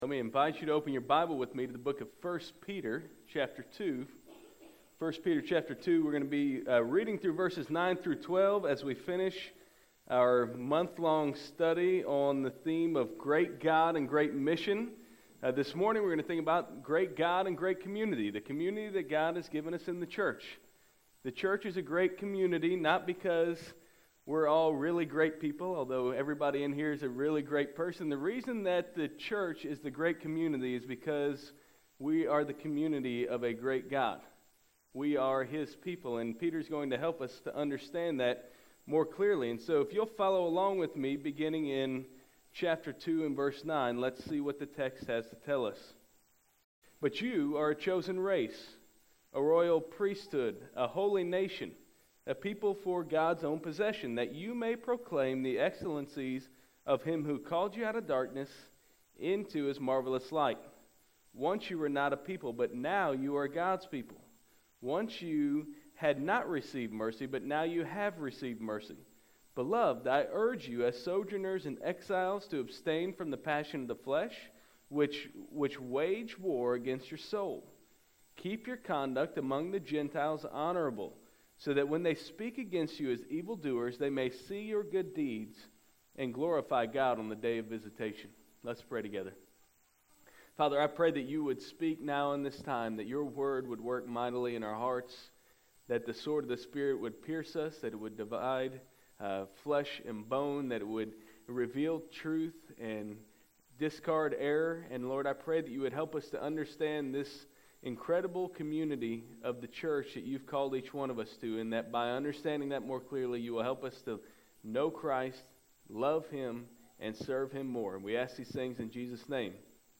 January 27, 2013 AM Worship | Vine Street Baptist Church